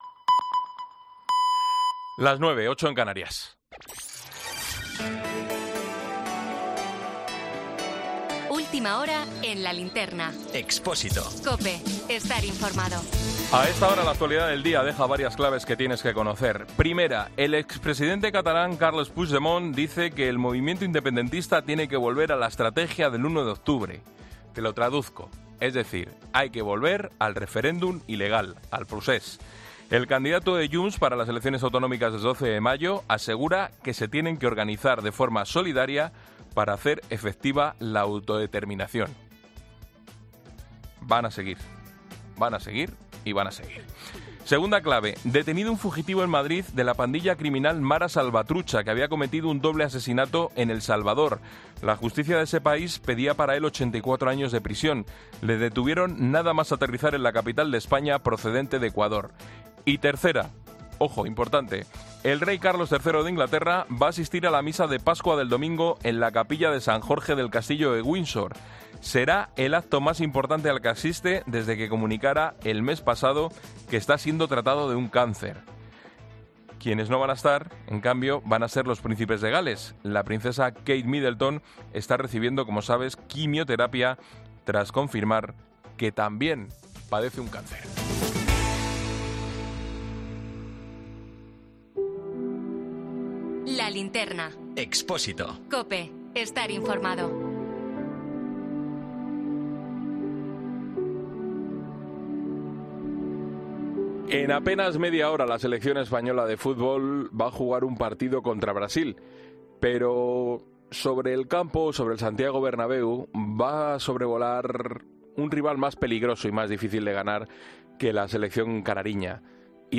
Boletín 21.00 horas del 26 de marzo de 2024 La Linterna